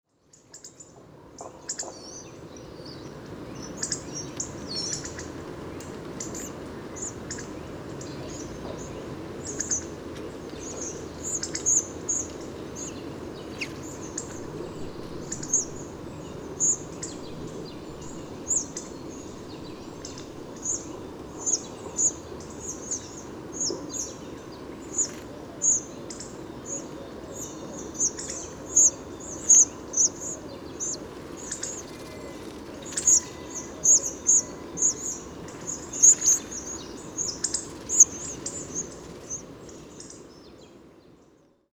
Since one week a flock of 25 Hawfinches C. coccothraustes was straying around.
With a “contact call” the flock seemed to sync their activities.
090213, Hawfinch, calls, Leipzig, Germany
hawfinches_contact_calls.mp3